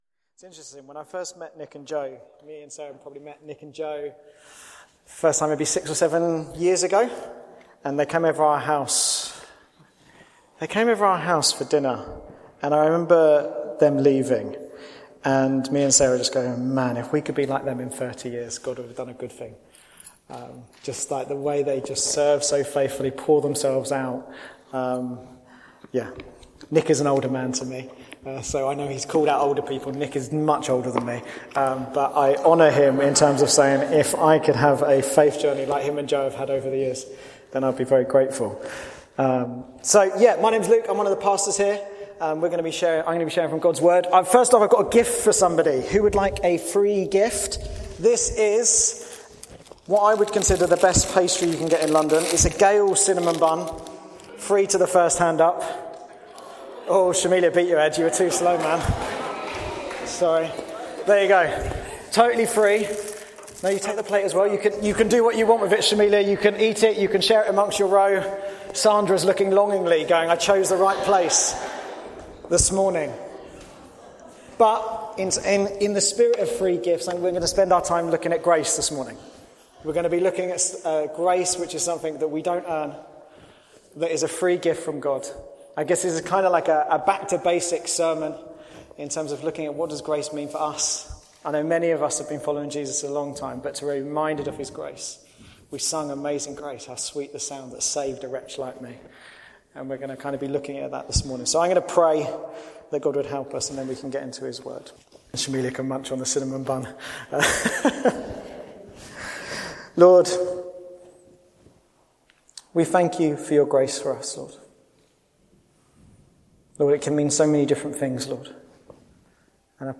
The Church in Bassett Street Podcast Archive